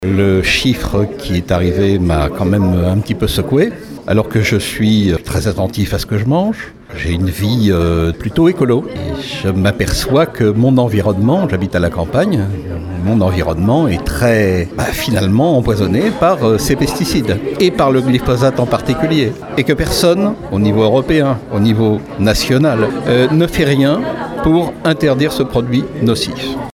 Ce matin, devant le TGI de La Rochelle.